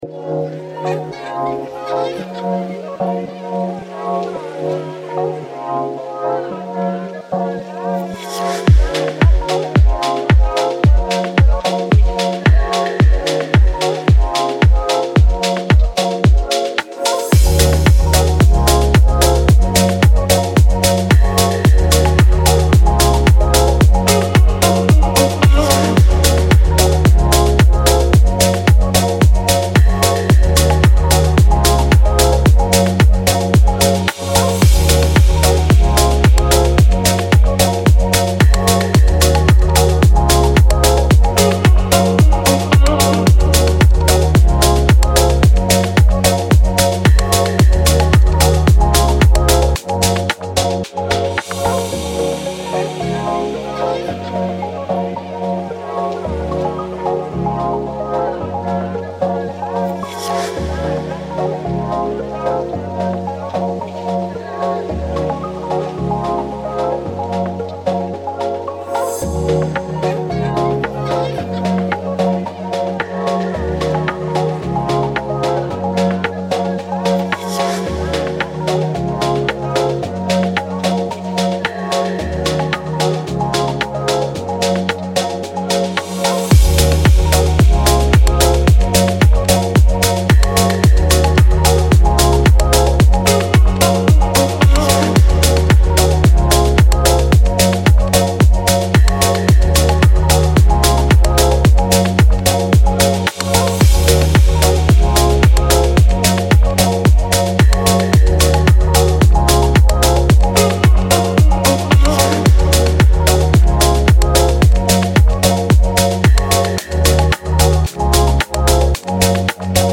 ۶. الکترونیک آرام (Mellow Electronic)